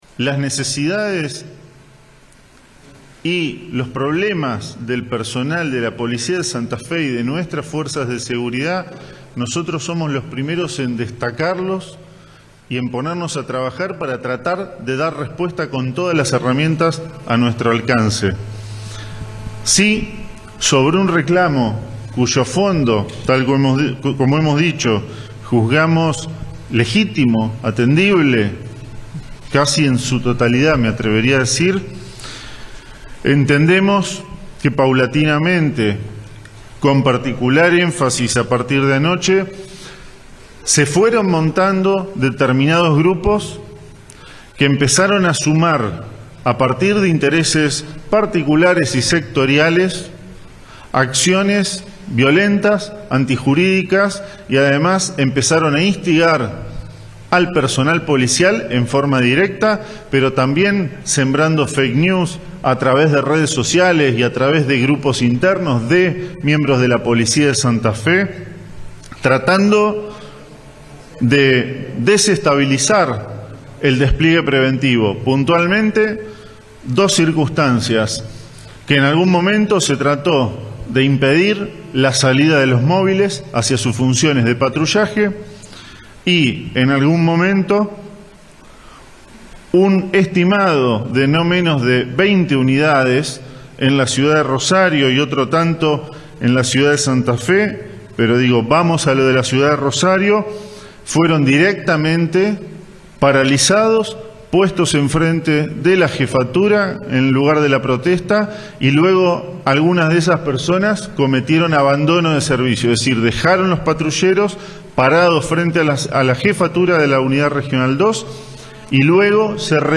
El funcionario provincial realizó las declaraciones en una conferencia de prensa para referirse a la los planteos que realiza un sector de la Policía santafesina.
Pablo Cococcioni, ministro de Seguridad